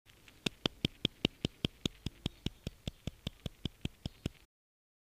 拍手の音